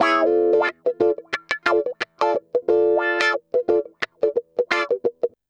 Index of /90_sSampleCDs/USB Soundscan vol.04 - Electric & Acoustic Guitar Loops [AKAI] 1CD/Partition C/05-089GROWAH